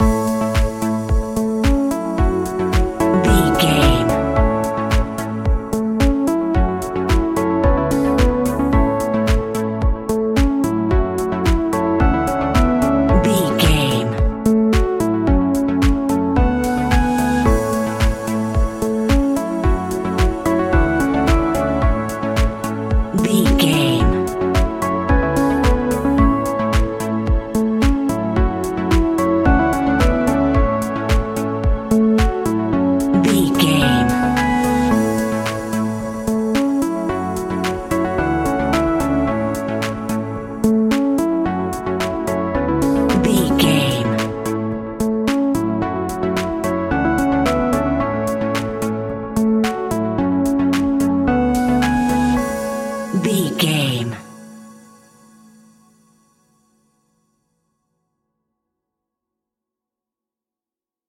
Ionian/Major
groovy
uplifting
driving
energetic
repetitive
synthesiser
drums
electric piano
electronic
techno
synth leads
synth bass